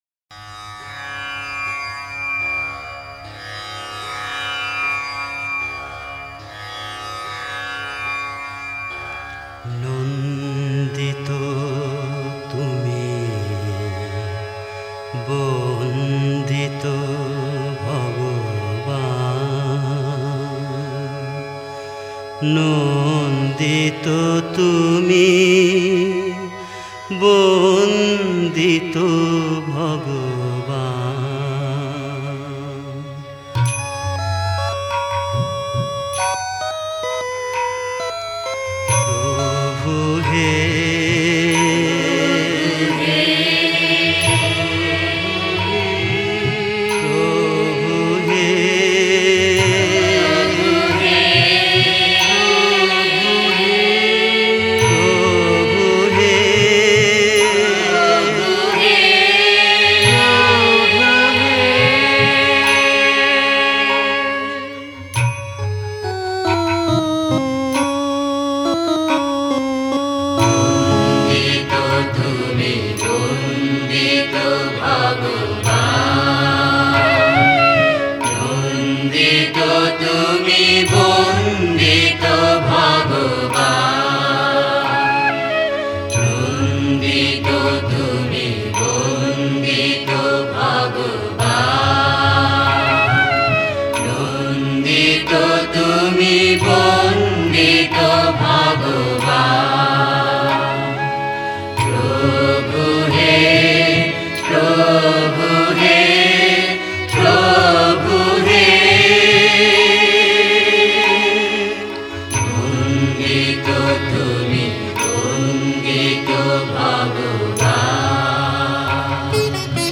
Directory Listing of mp3files/Bengali/Devotional Hymns/Bhajons/ (Bengali Archive)